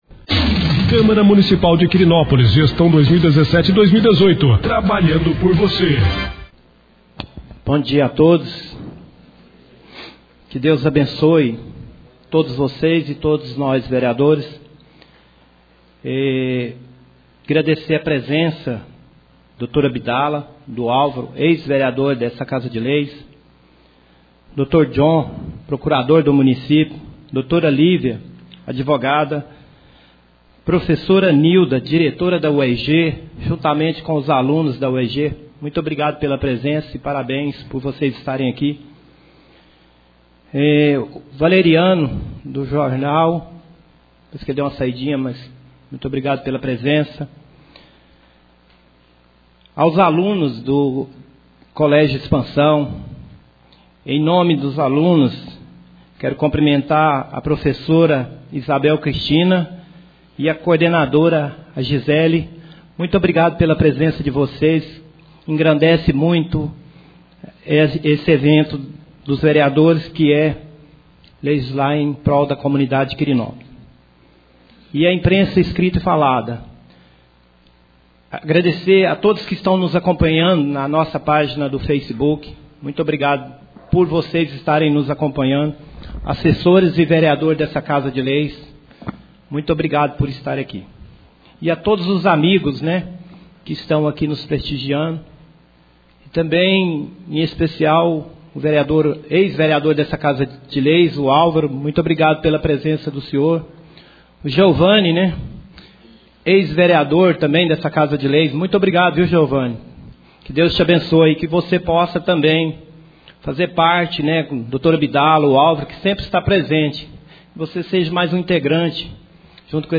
2ª Sessão Ordinária do mês de Outubro 2017